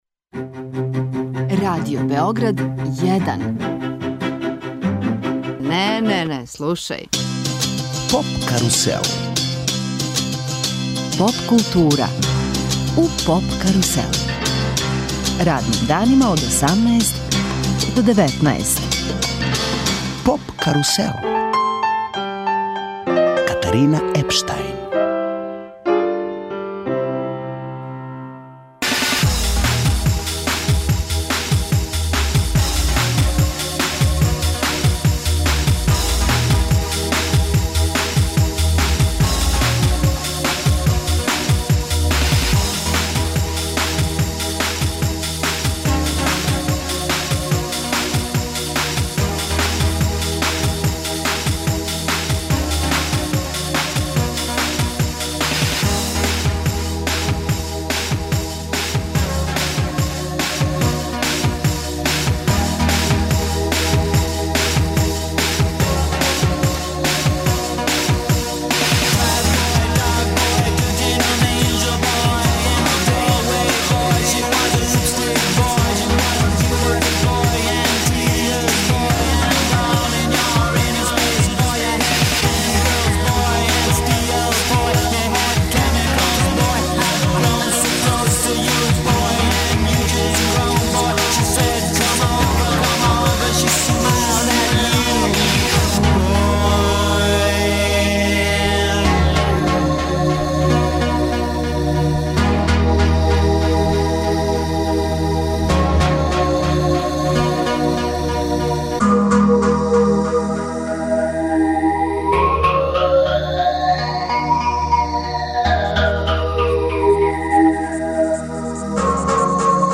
Сваког дана резимирамо претходну ноћ, слушамо извођаче и преносимо део атмосфере.